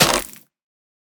Minecraft Version Minecraft Version snapshot Latest Release | Latest Snapshot snapshot / assets / minecraft / sounds / block / mangrove_roots / break5.ogg Compare With Compare With Latest Release | Latest Snapshot
break5.ogg